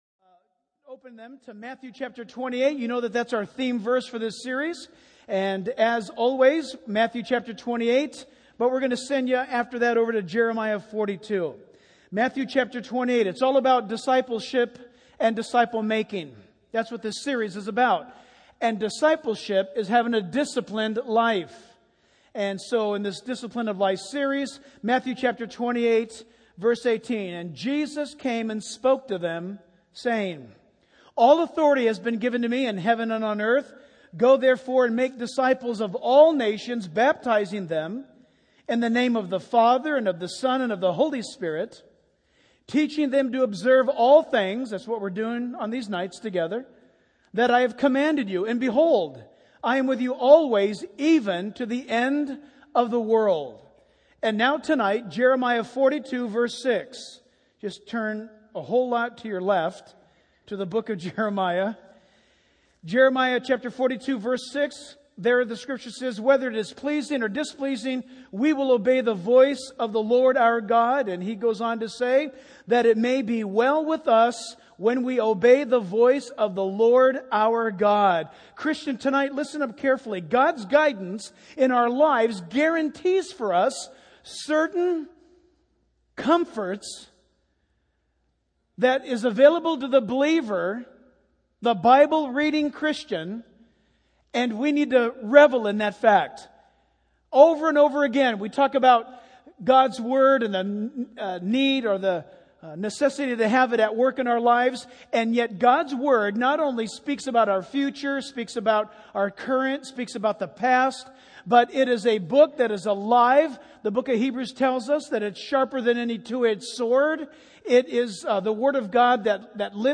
The sermon concludes with a call to trust in God's perfect plan and to obey His guidance for a fulfilling life.